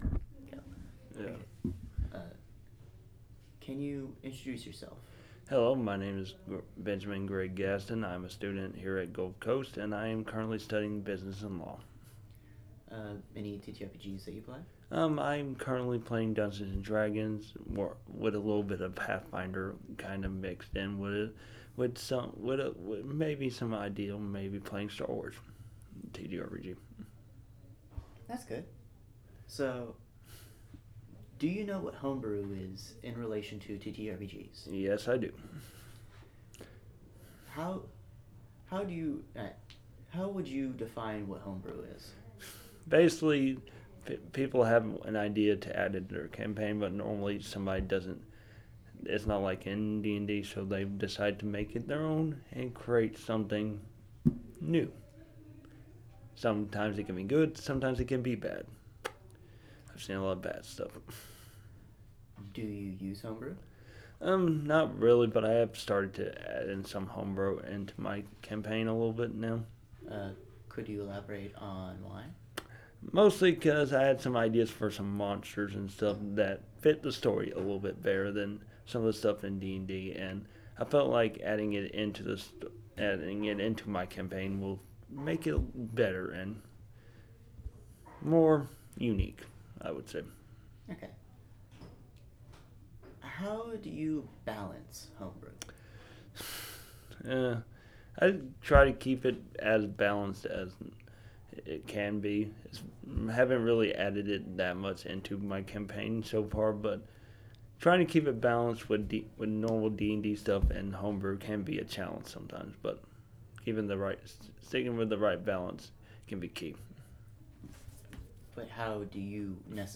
In this article two members of the TTRPG Club are interviewed to learn more about homebrew, it’s uses, and its value.